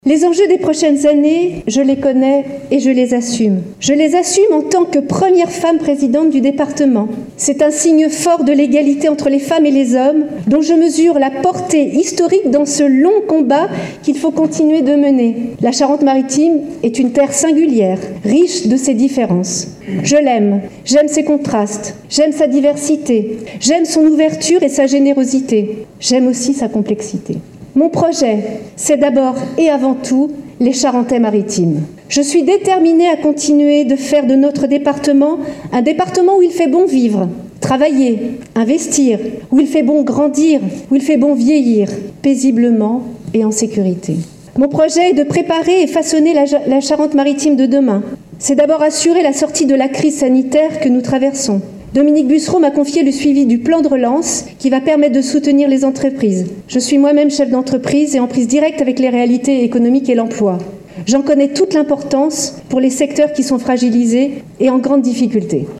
Lors de son installation, Sylvie Marcilly a prononcé un discours au cours duquel elle a présenté ses priorités, après avoir salué le travail accompli par son prédécesseur Dominique Bussereau qui a occupé cette fonction pendant 13 ans, tout en le félicitant pour sa nomination en tant que président du Conseil départemental honoraire.